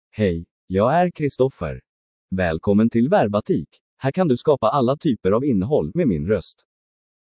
MaleSwedish (Sweden)
Voice sample
Male
Swedish (Sweden)